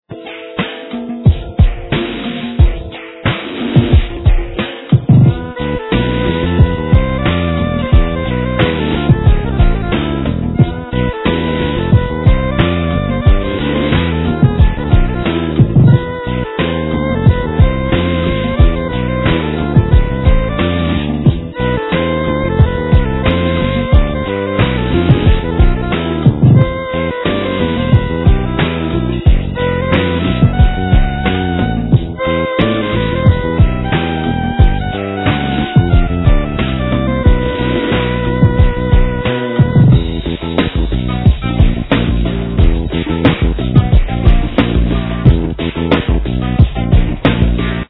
Saxophone, Synthsizer
Otera, Utogardon, Mouth harp